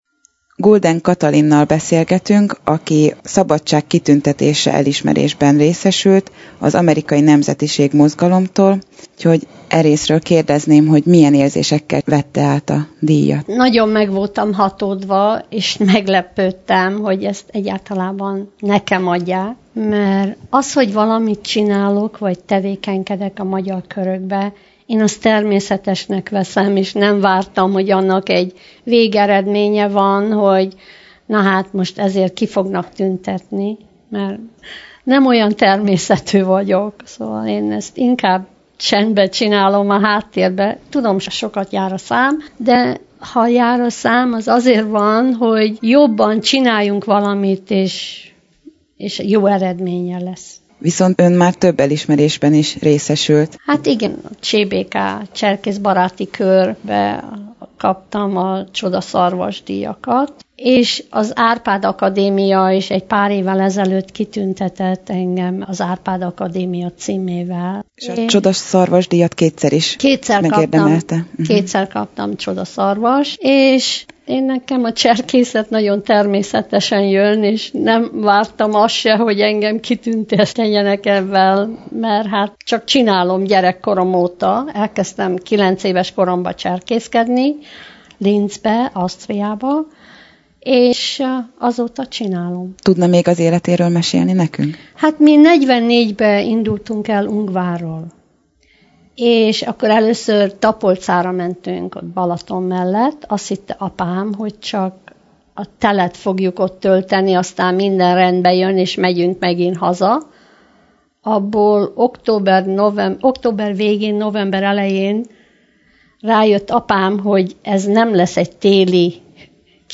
Az interjú itt is meghallgatható a vasárnapi élő adás után.